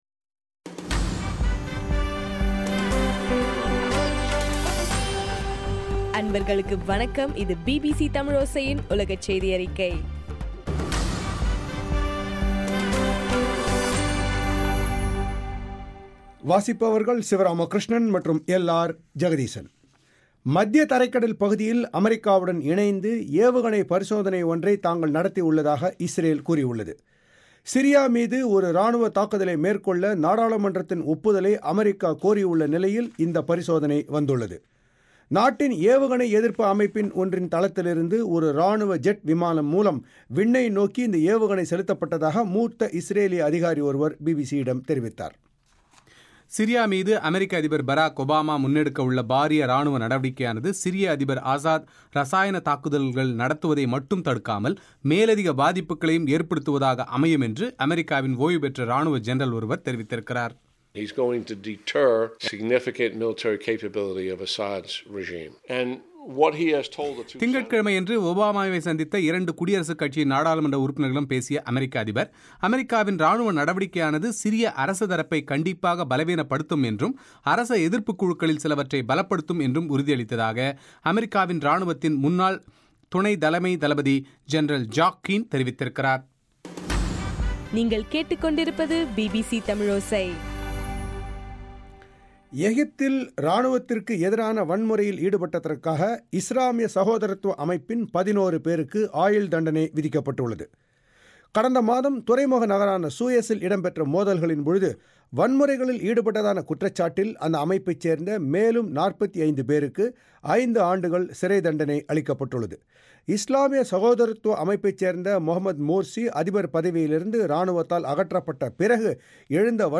செப்டம்பர் 3 பிபிசி தமிழோசை உலகச் செய்தி அறிக்கை- இலங்கை ஷக்தி எப் எம்-இல் ஒலிபரப்பானது